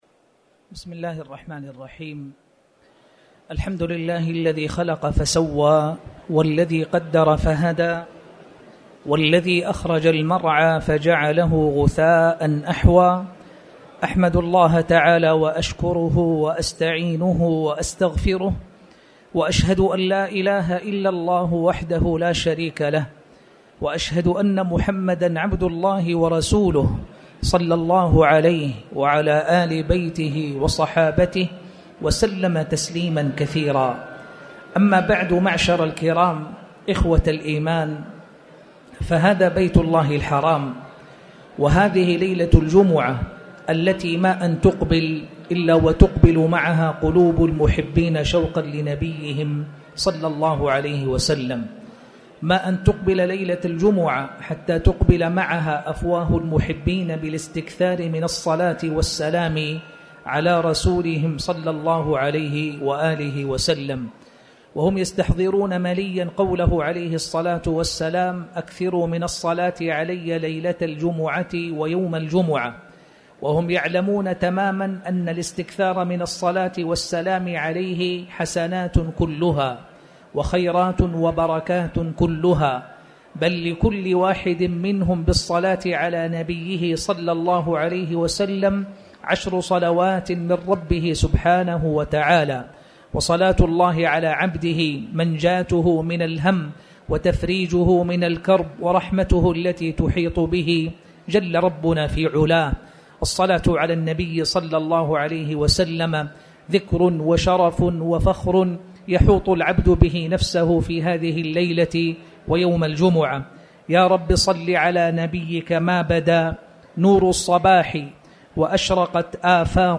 تاريخ النشر ١٧ شعبان ١٤٣٩ هـ المكان: المسجد الحرام الشيخ